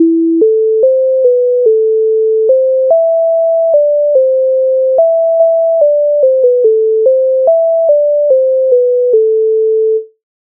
MIDI файл завантажено в тональності a-moll
Ой вербо вербо, Українська народна пісня з обробок Леонтовича с. 124
Ukrainska_narodna_pisnia_Oj_verbo_verbo_.mp3